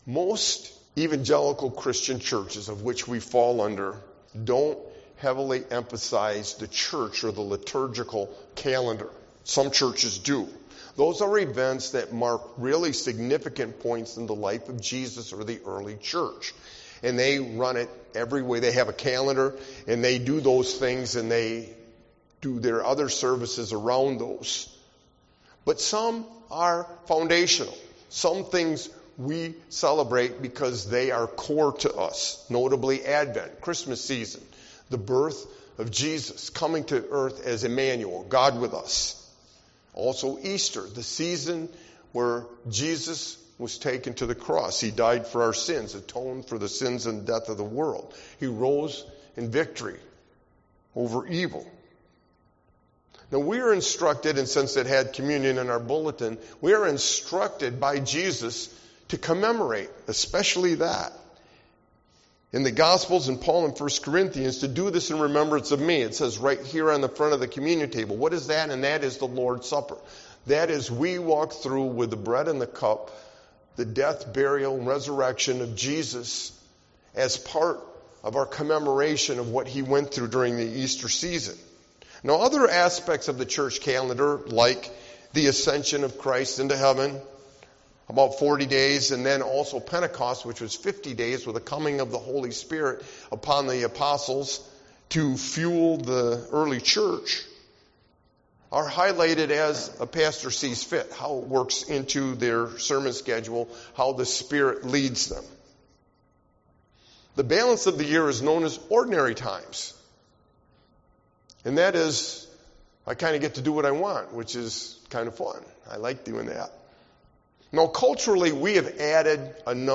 Sermon-Snapshots-of-Mary-5822.mp3